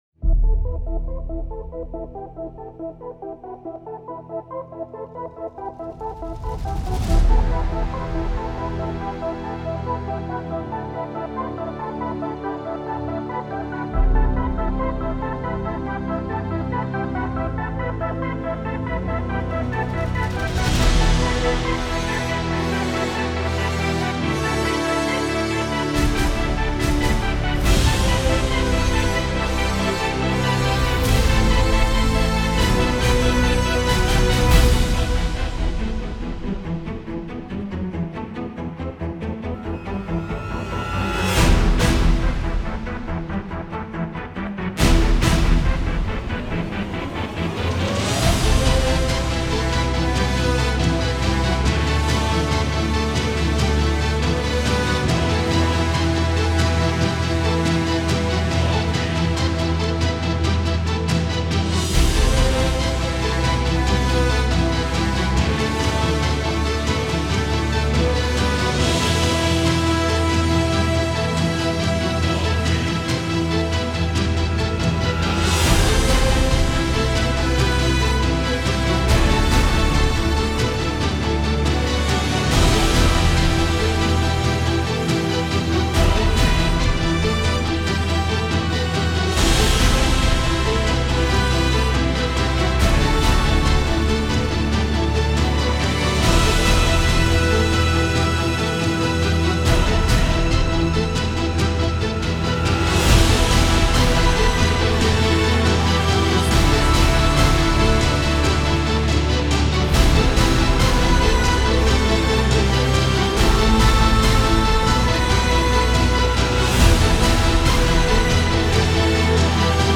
سبک اپیک , موسیقی بی کلام
موسیقی بی کلام ارکسترال